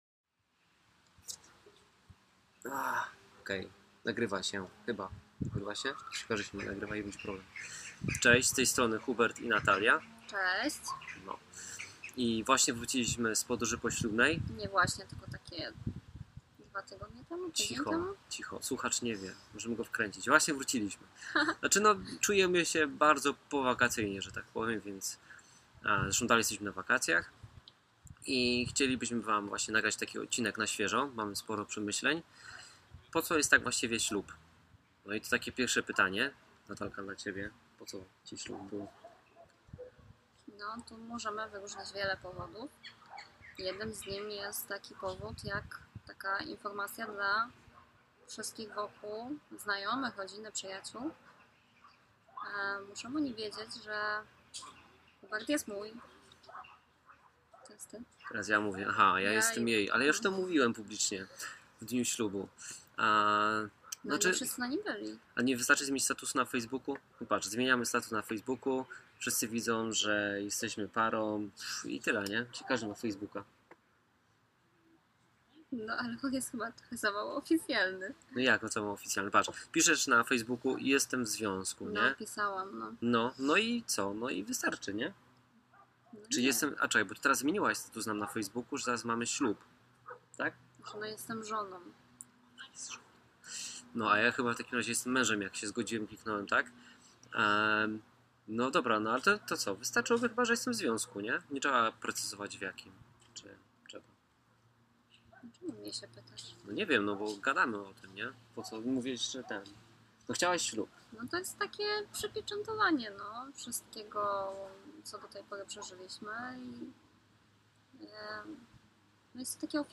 W odcinku od młodej pary dowiesz się co daje ślub, jaki ślub wybrać oraz otrzymasz garść porad jak to wszystko zorganizować. Dodatkowo dwa wywiady, w tym jeden w 50 rocznicę ślubu.